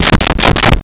Door Knock Muffled
Door Knock Muffled.wav